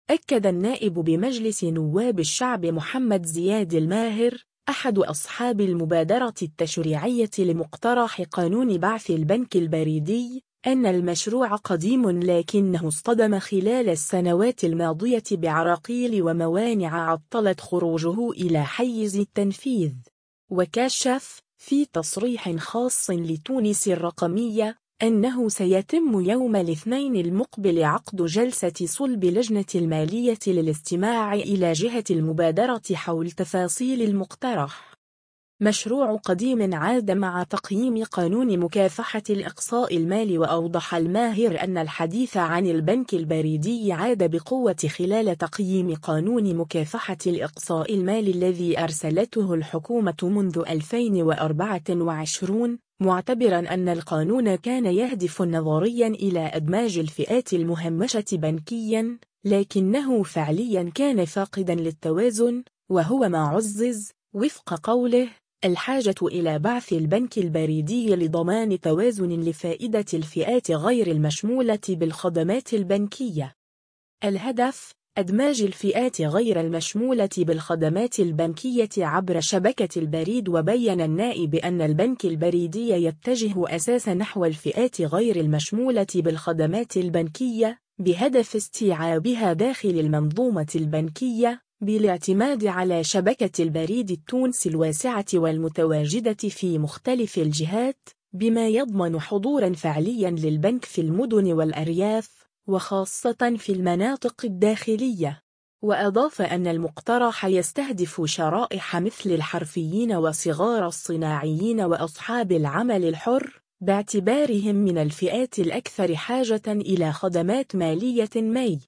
أكد النائب بمجلس نواب الشعب محمد زياد الماهر، أحد أصحاب المبادرة التشريعية لمقترح قانون بعث البنك البريدي، أن المشروع “قديم” لكنه اصطدم خلال السنوات الماضية بـعراقيل وموانع عطّلت خروجه إلى حيّز التنفيذ. وكشف، في تصريح خاص لـ”تونس الرقمية”، أنه سيتم يوم الاثنين المقبل عقد جلسة صلب لجنة المالية للاستماع إلى جهة المبادرة حول تفاصيل المقترح.